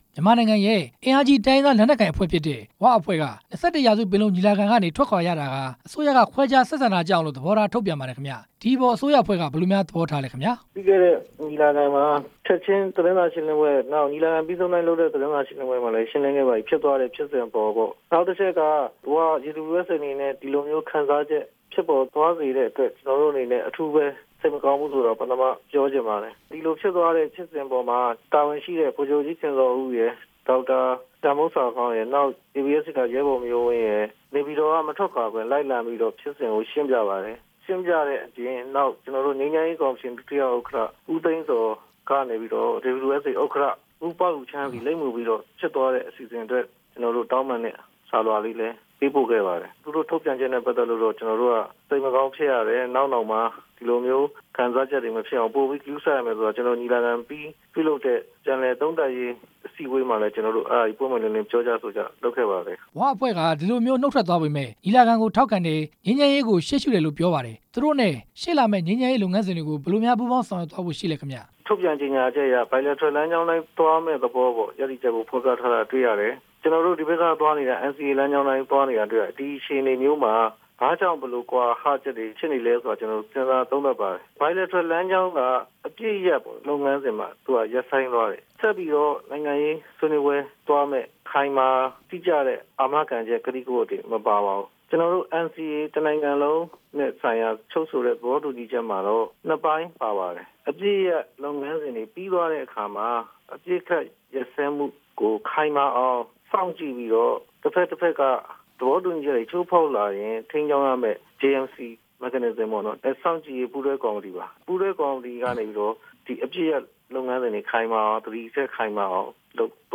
သမ္မတရုံးဝန်ကြီးဌာန ပြောရေးဆိုခွင့်ရှိသူ ဦးဇော်ဌေးနဲ့ မေးမြန်းချက်